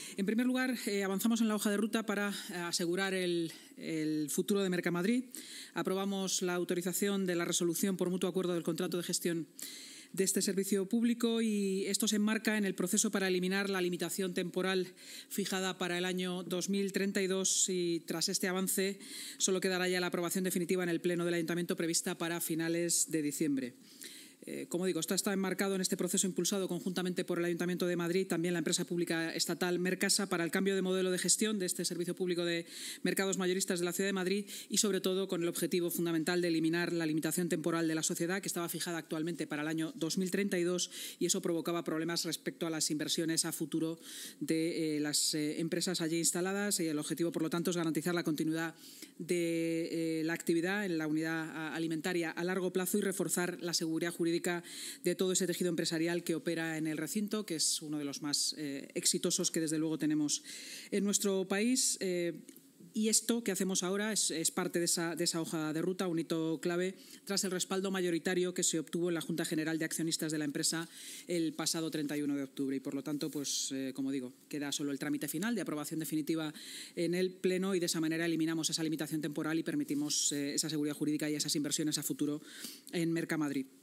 La Junta de Gobierno del Ayuntamiento de Madrid ha aprobado hoy el acuerdo por el que se autoriza la resolución por mutuo acuerdo del contrato de gestión del servicio público de Mercamadrid, aprobado en su día bajo el modelo de sociedad de economía mixta, según ha informado la vicealcaldesa de Madrid y portavoz municipal, Inma Sanz, en rueda de prensa tras la Junta.